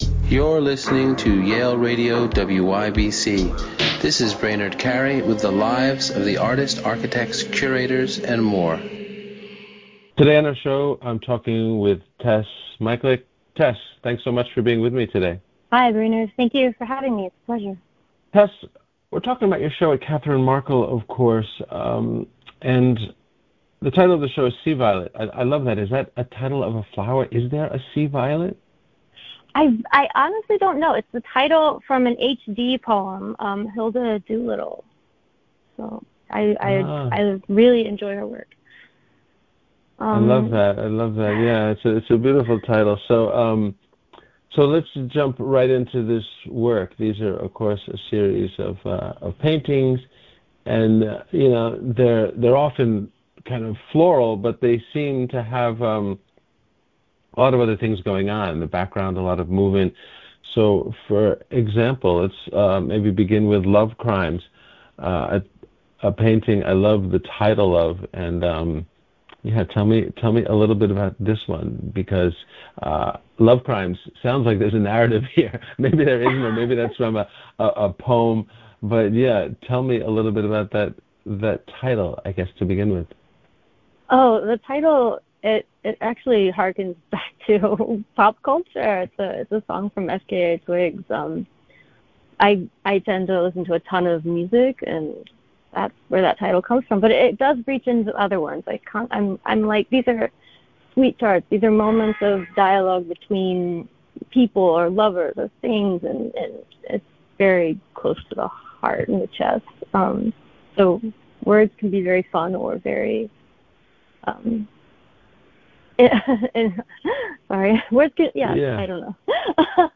Interviews from Yale University Radio WYBCX